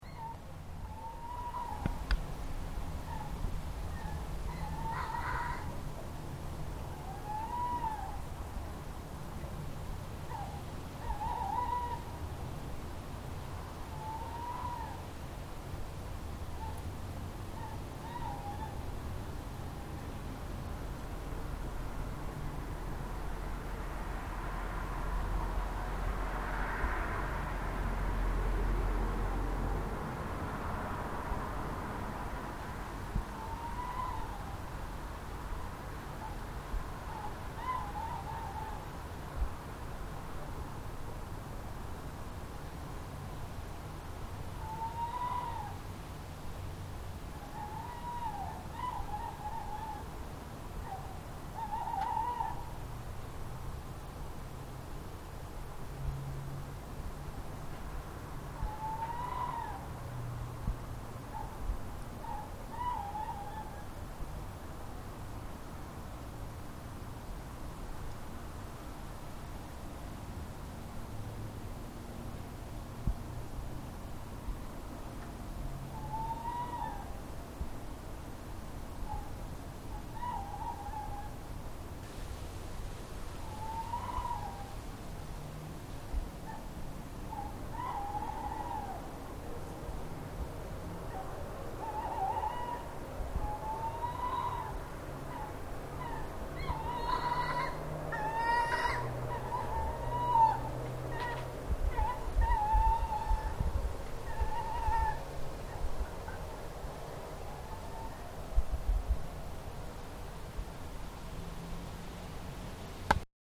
Last week as I was getting ready to retire for the evening I could hear a strange noise drifting over the gardens.
It had an eerie feel to it, so I immediately grabbed the Zoom H4n, popped the Rycote on top and set out to investigate.
It sounded like it was coming from a couple of gardens down. I stayed as still as I could and listened as it got closer.
animal-sound-edit.mp3